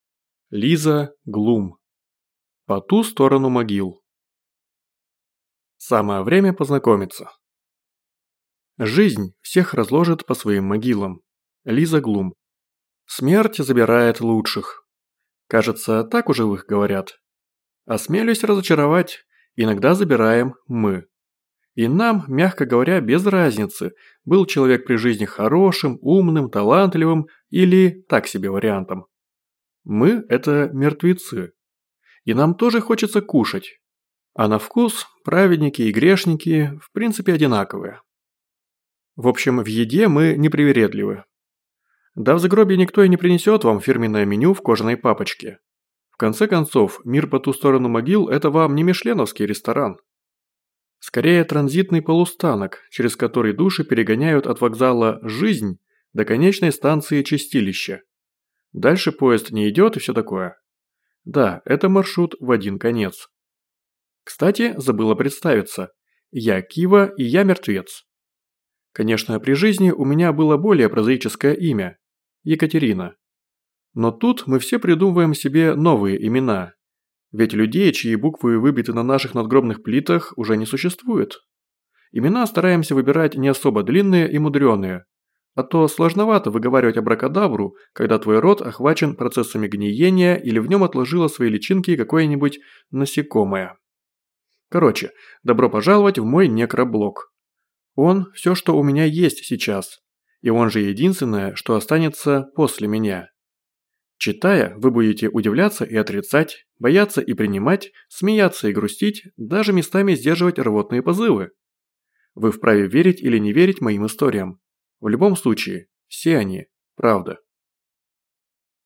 Аудиокнига По ту сторону могил | Библиотека аудиокниг